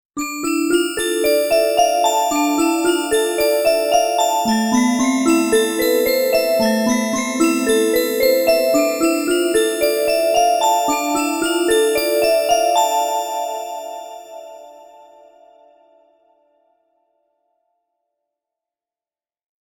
Sparkling synth bells create a magical and whimsical sound.